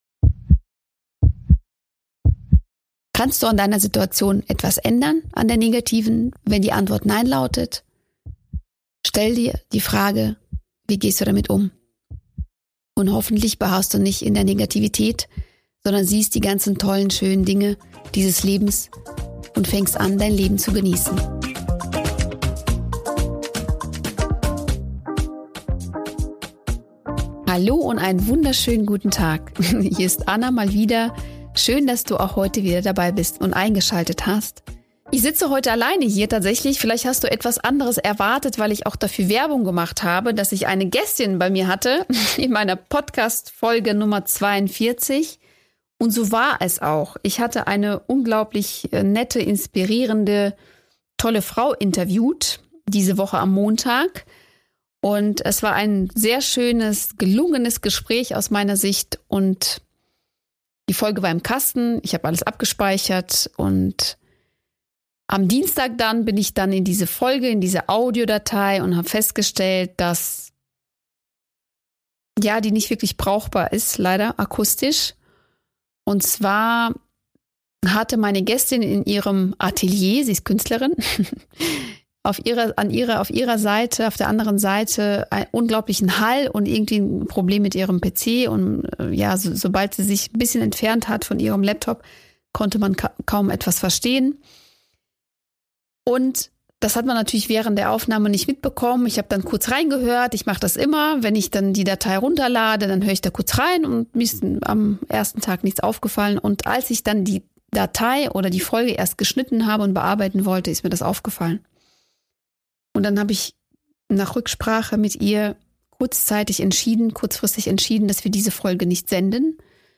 Es läuft nicht immer so, wie wir es uns wünschen und wie wir es erwarten. Auch ich habe für heute etwas anderes geplant, als alleine zu euch zu sprechen. Was ich mal wieder selbst daraus gelernt habe und woran ich mich wieder erinnert habe – hört ihr in dieser Folge 42.